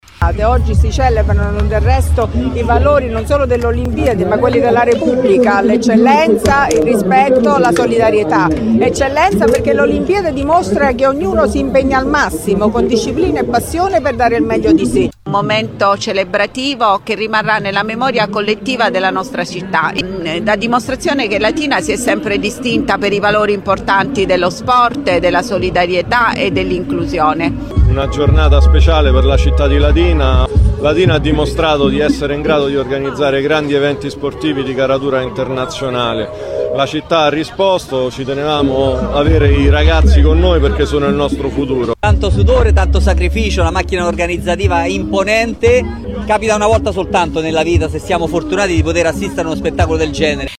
Ad attenderlo sul palco la prefetta Vittoria Ciaramella con la sindaca Matilde Celentano e gli assessori Andrea Chiarato allo Sport e Gianluca Di Cocco al Turismo che hanno celebrato un vero e proprio evento cittadino